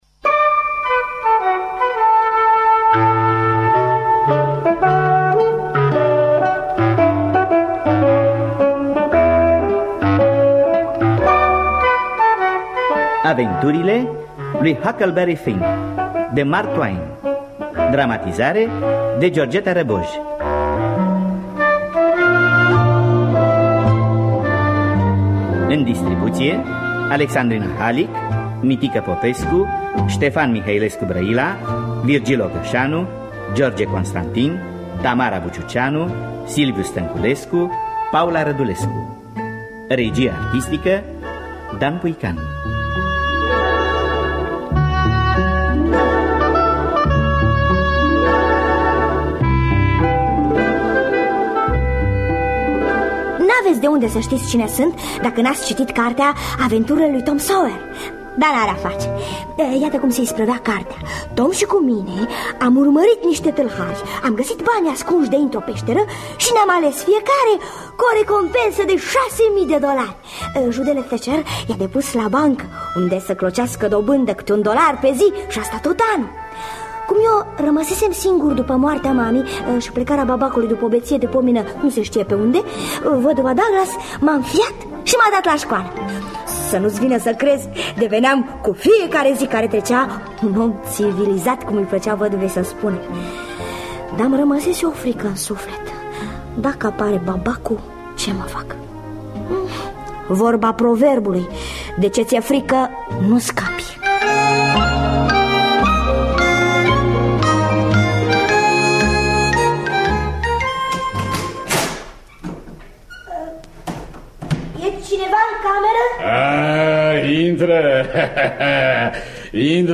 Dramatizare